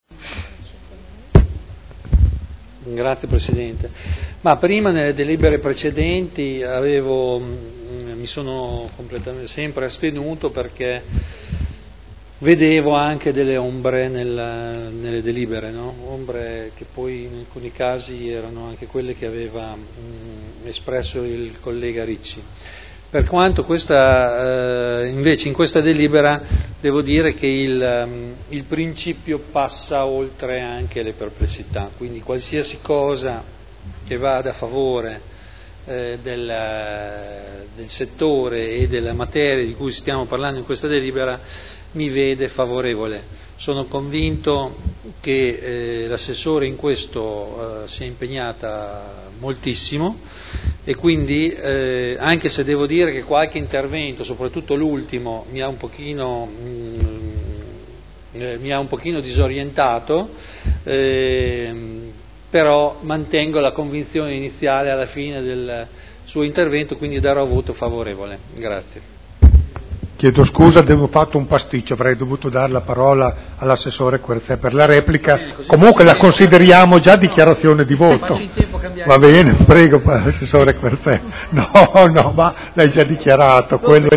Dichiarazione di voto.